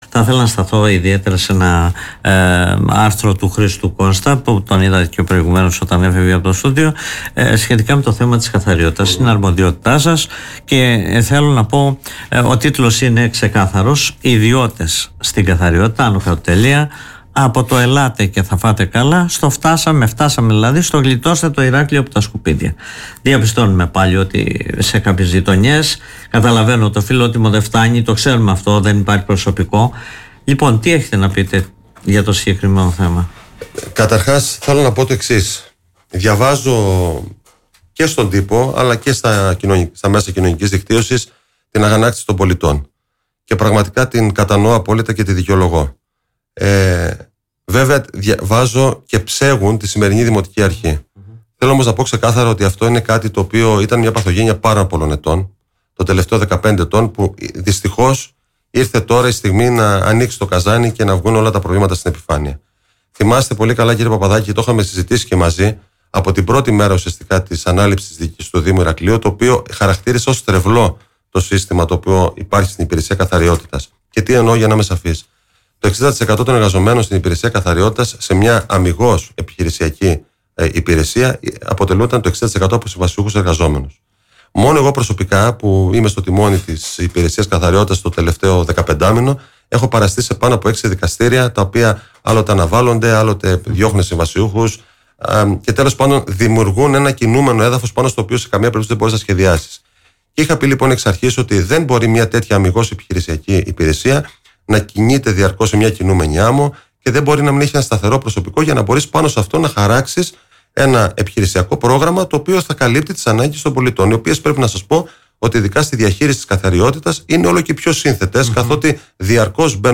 Απάντηση έδωσε ο αντιδήμαρχος καθαριότητας Νίκος Γιαλιτάκης μέσω της εκπομπής “Δημοσίως”